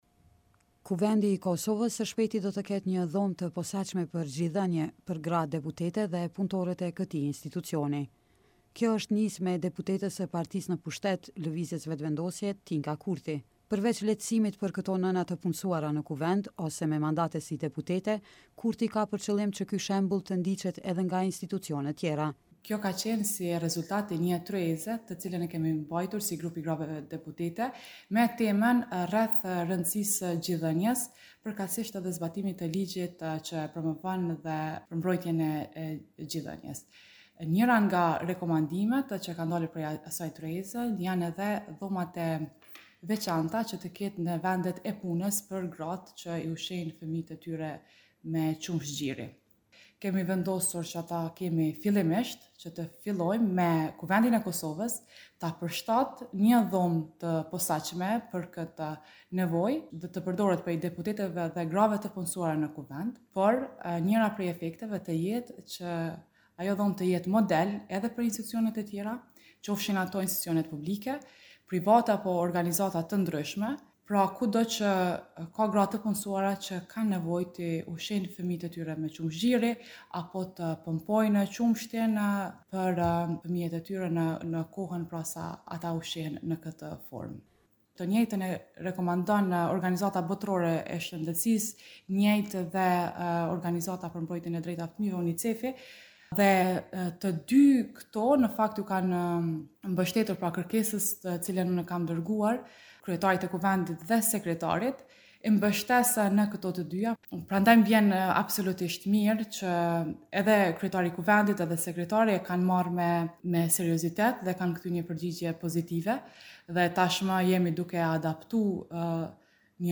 Në një bisedë me Radion Evropa e Lirë, Kurti ndanë detaje lidhur me këtë nismë, me shpresë që shembulli të ndiqet edhe nga institucionet tjera.